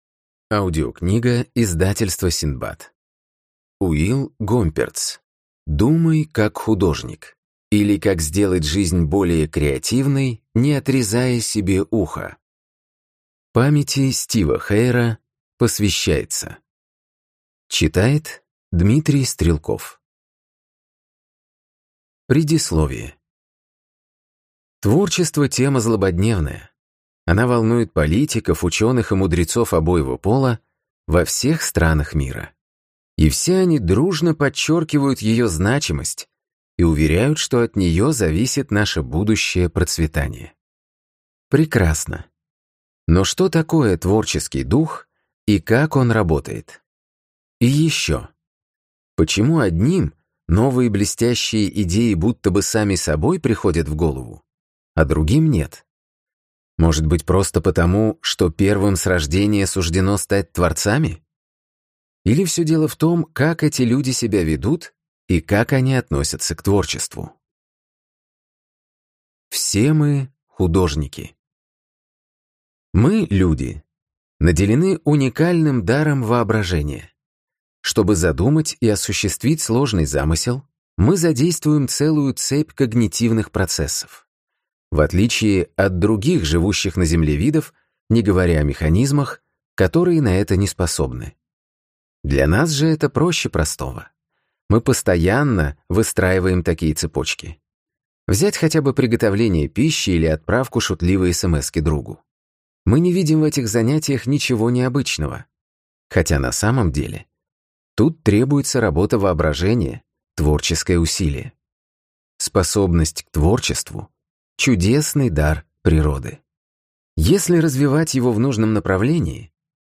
Аудиокнига Думай как художник, или Как сделать жизнь более креативной, не отрезая себе ухо | Библиотека аудиокниг